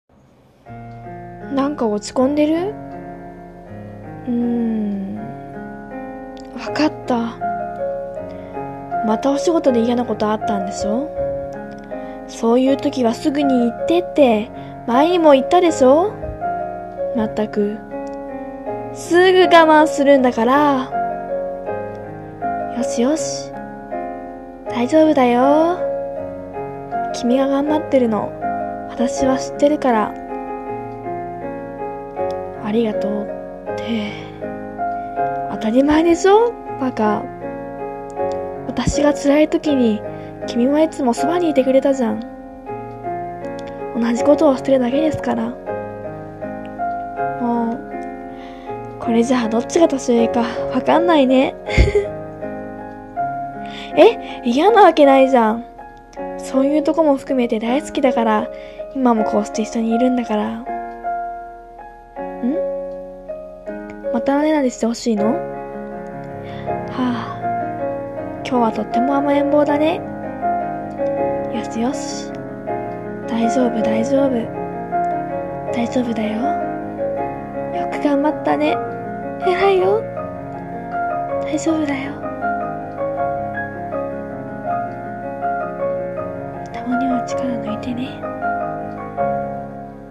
声劇[大丈夫]【１人声劇】